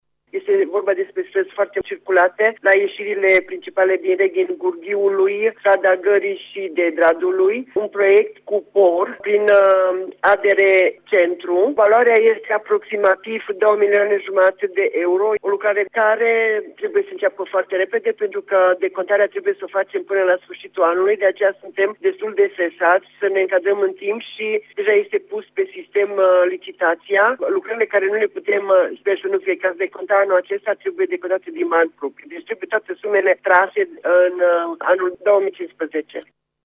Primarul Reghinului, Maria Precup, a explicat că sunt străzi importante, foarte circulate, la ieșirile principale din municipiu: